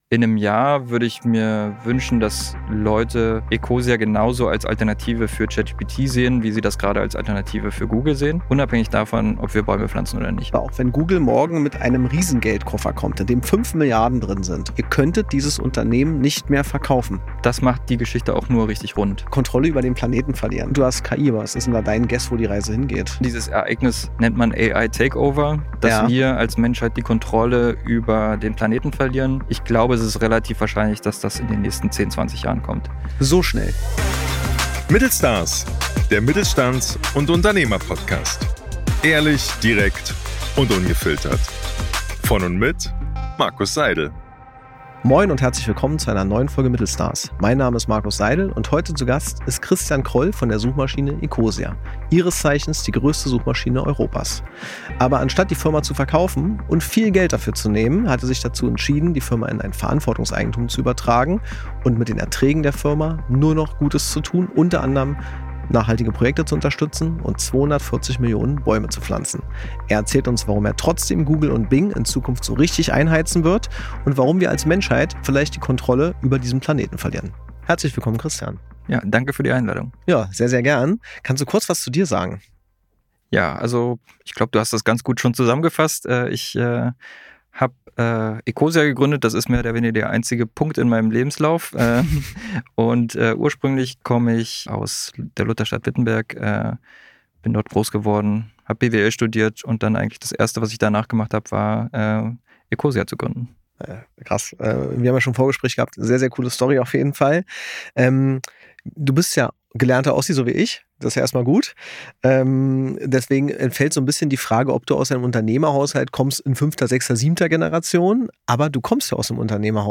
Ein ehrliches Gespräch über Verantwortung statt Exit, Technologie mit Haltung und die Frage, ob Wirtschaft nicht auch gut sein kann.